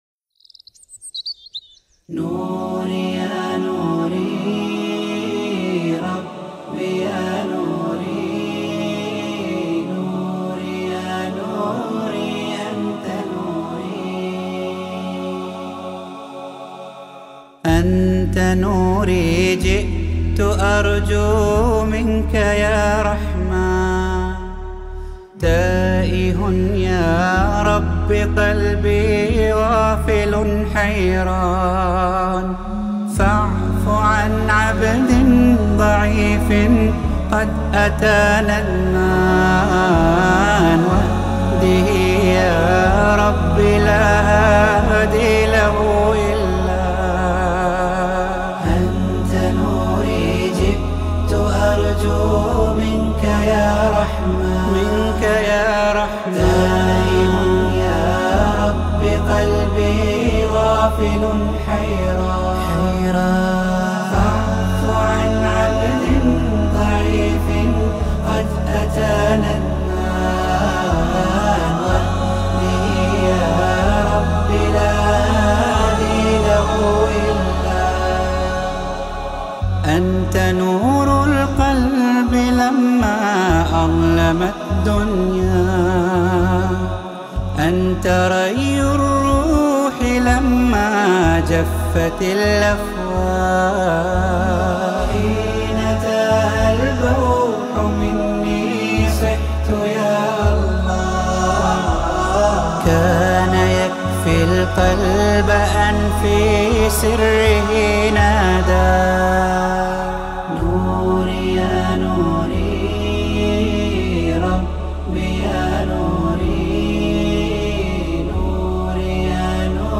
البوم كامل بدون موسيقى